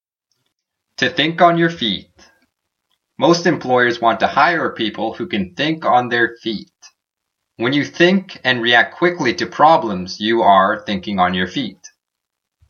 機転が利き、決断が早いという、当意即妙な様子を意味するイディオムです。 英語ネイティブによる発音は下記のリンクをクリックしてください。
tothinkonyourfeet.mp3